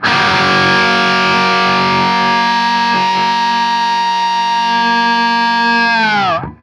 TRIAD F#  -R.wav